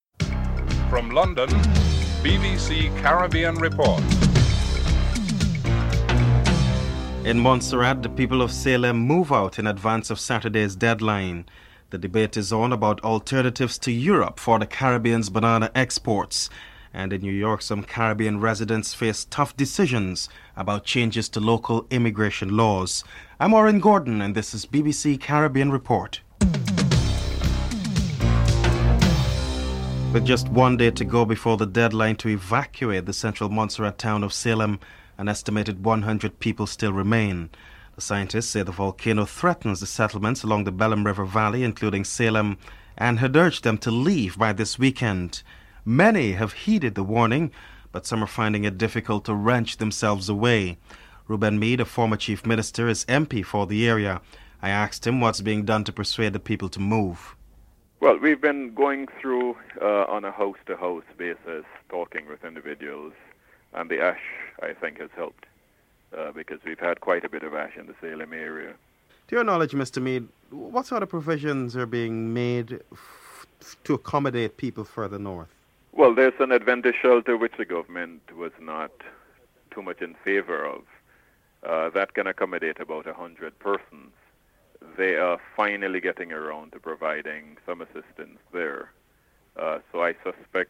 1. Headlines (00:00-00:29)
Member of Parliament, Reuben Meade is interviewed (00:30-04:00)